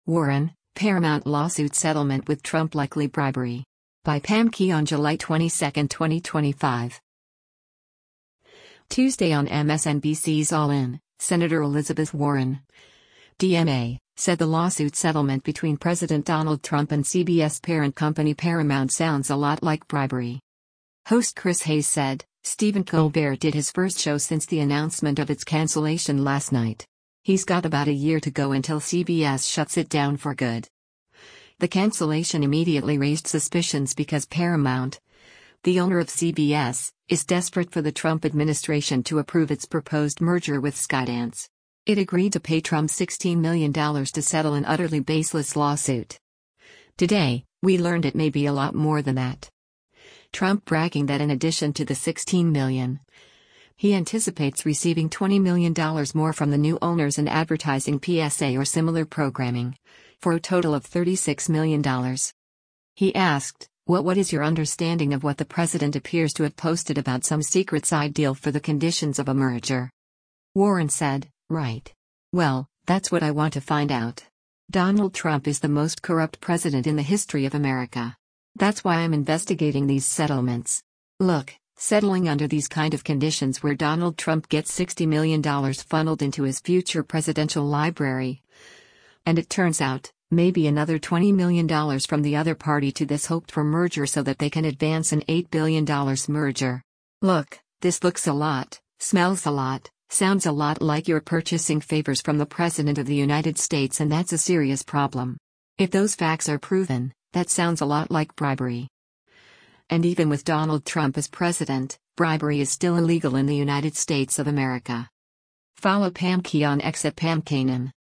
Tuesday on MSNBC’s “All In,” Sen. Elizabeth Warren (D-MA) said the lawsuit settlement between President Donald Trump and CBS parent company Paramount “sounds a lot like bribery.”